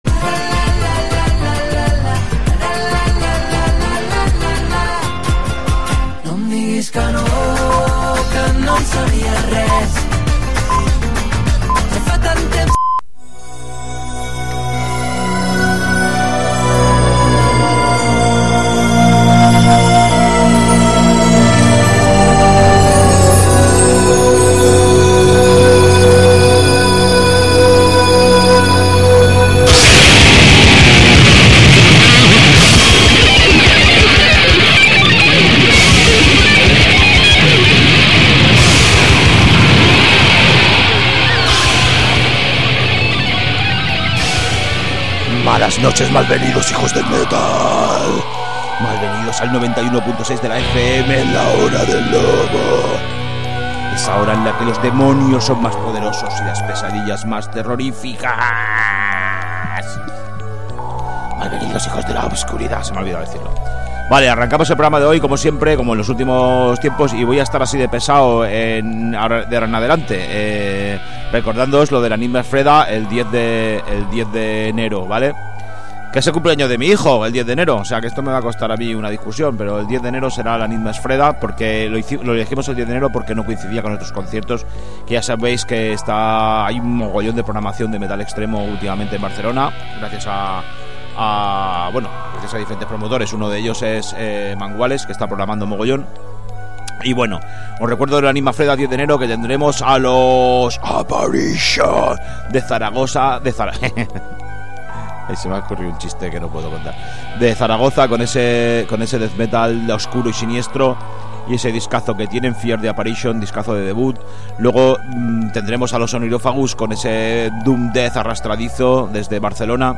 A 'La hora del lobo', ens arrossegarem per tots els subgèneres del metal, submergint-nos especialment en les variants més fosques i extremes.